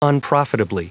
Prononciation du mot : unprofitably